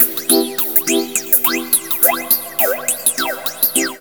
PIANOFXLP1-R.wav